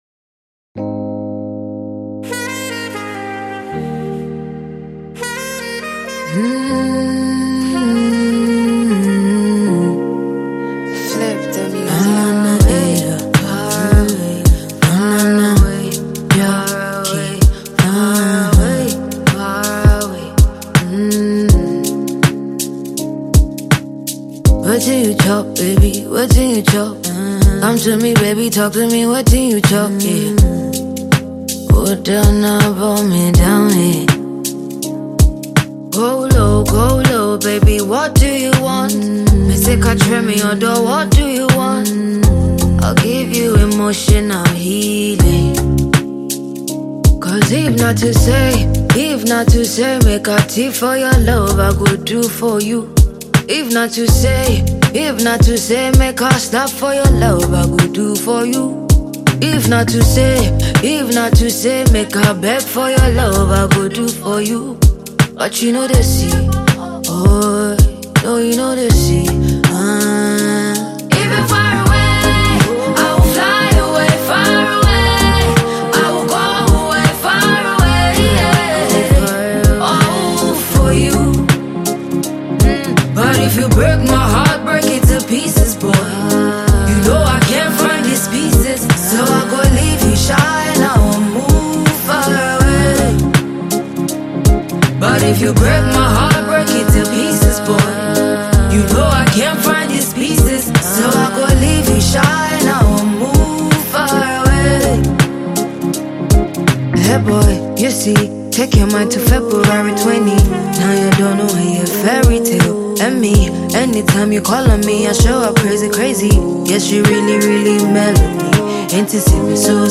afrobeat/afro singer